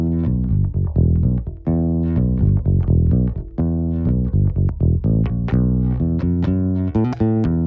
32 Bass PT2.wav